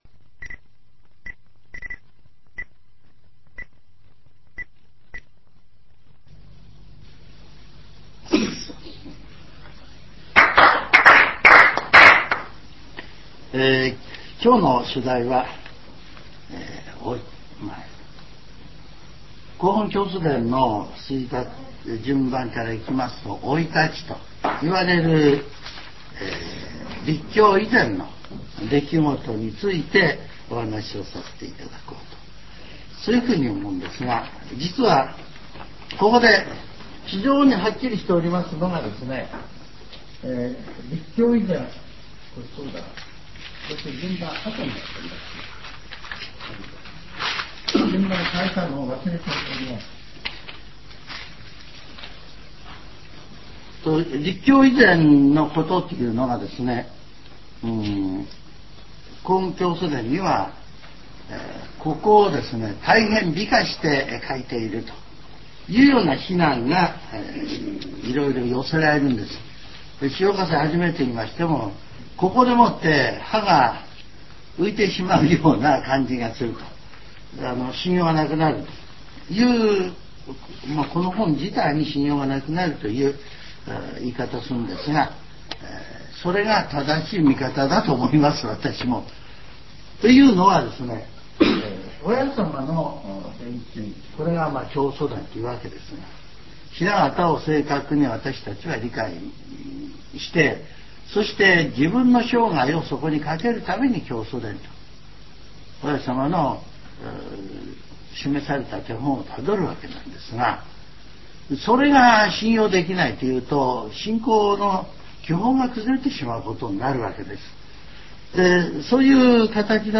全70曲中16曲目 ジャンル: Speech